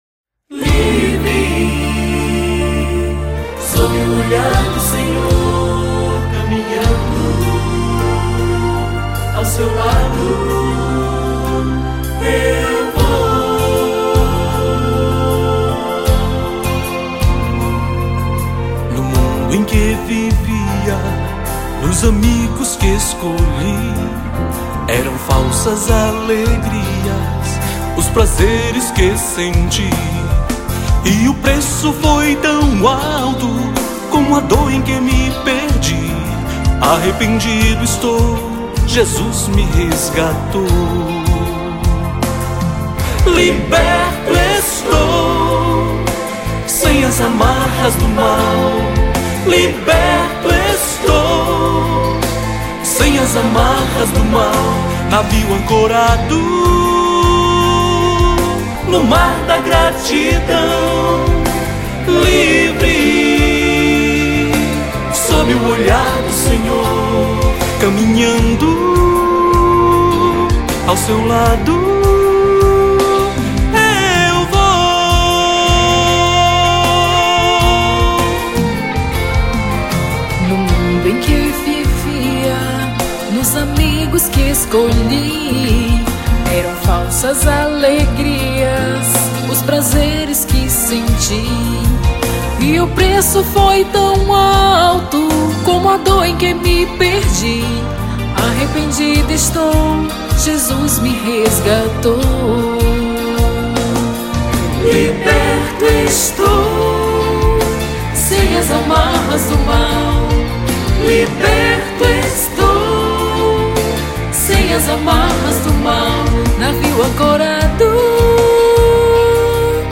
EstiloCatólica